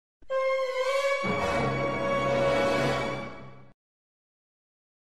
Панический саспенс от осознания плохого